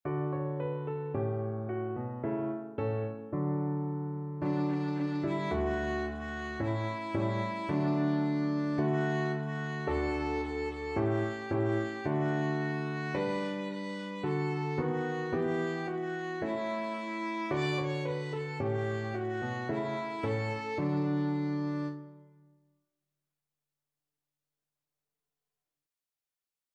Violin
D major (Sounding Pitch) (View more D major Music for Violin )
Fast =c.110
2/4 (View more 2/4 Music)
Traditional (View more Traditional Violin Music)